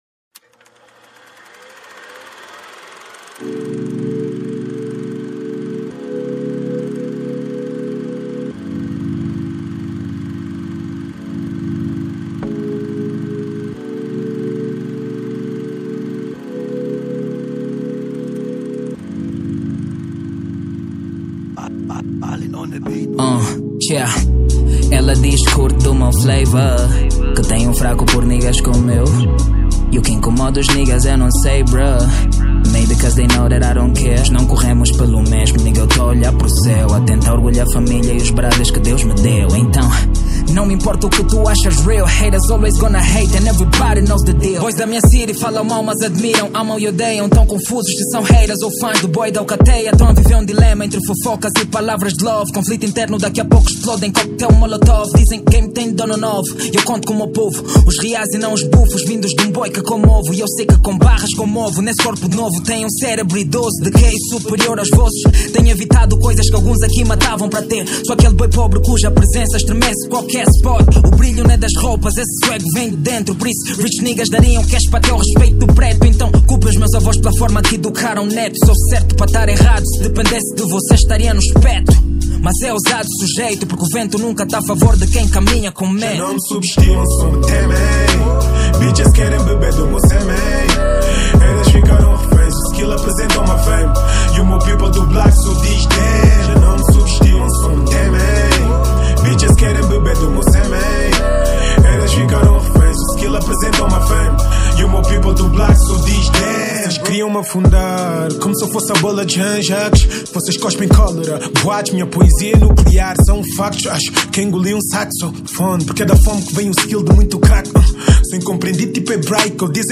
Estilo: Rap